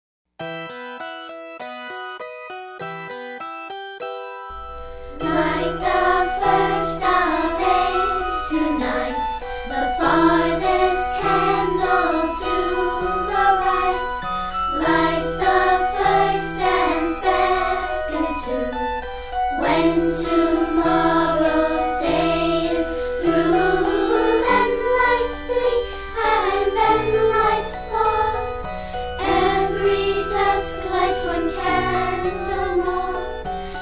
▪ The full-length music track with vocals.
Listen to a sample of this song.